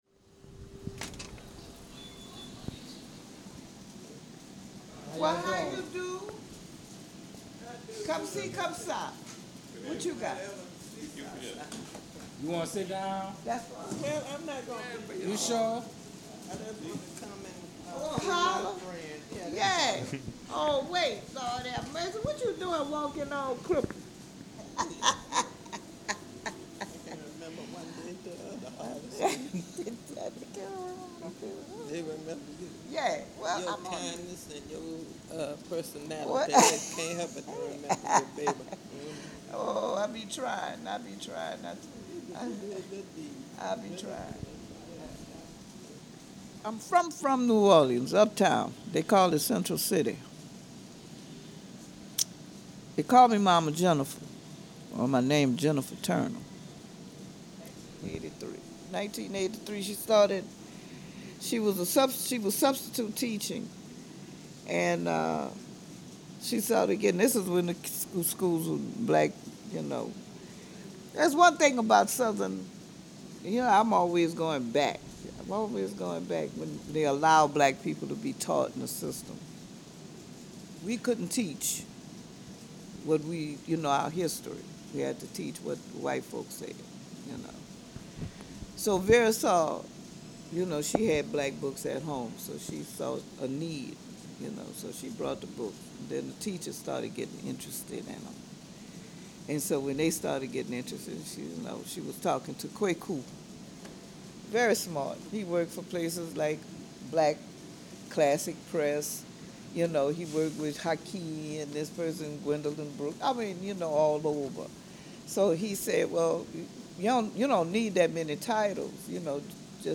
An audio collage created from a conversation inside the Community Book Center in New Orleans. This piece is part of a series documenting the unique experience and ambience held within Black literary space by the people who create and keep them going., American, (b.1996)